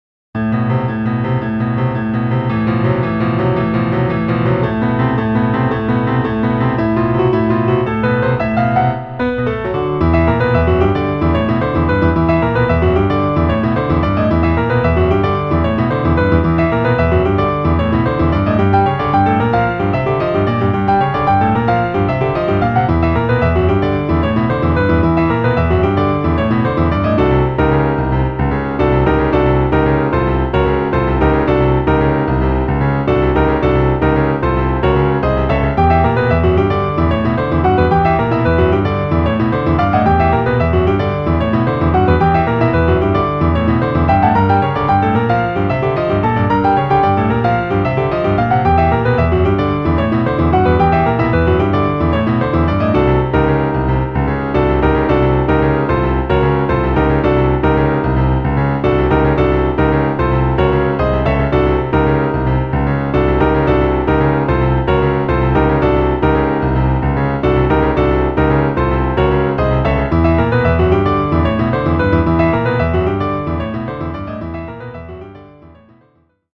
Ivory 3 German D